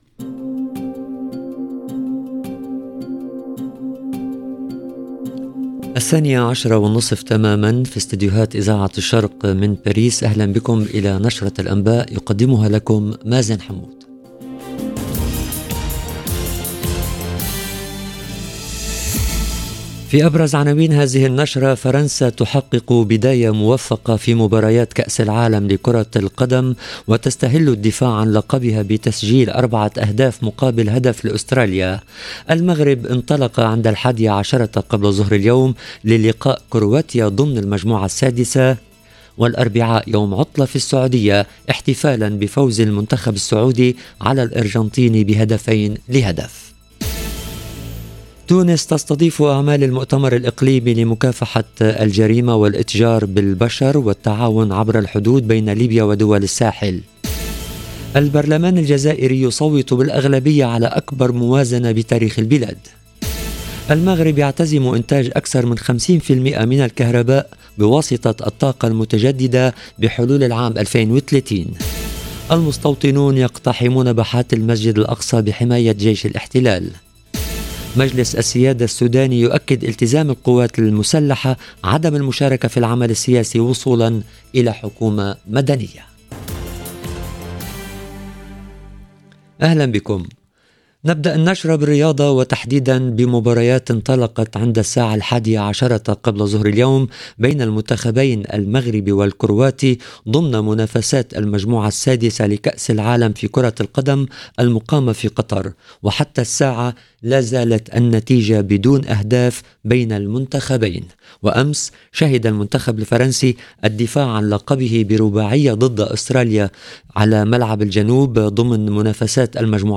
EDITION DU JOURNAL DE 12H30 EN LANGUE ARABE DU 23/11/2022